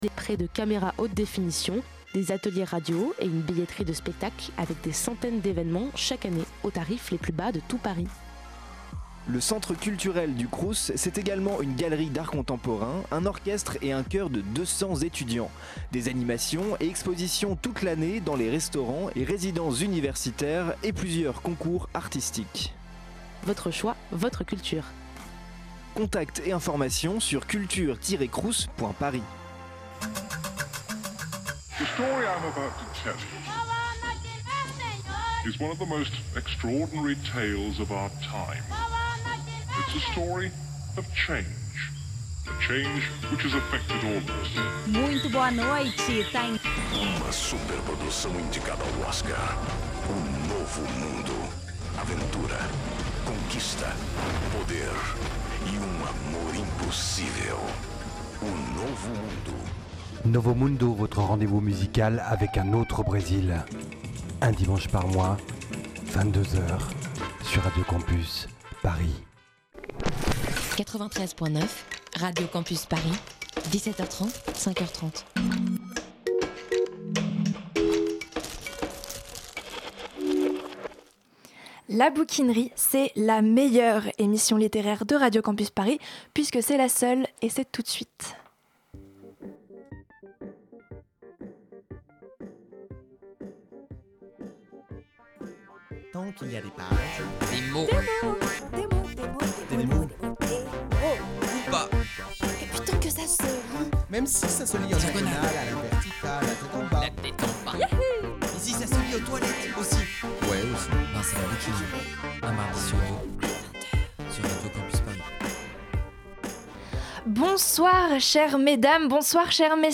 Pour vous présenter cette belle troupe, nous sommes nombreux en studio ce soir, les étagères de la bouquinerie ploient, croulent de bouquins et de bonnes ondes...
Entretien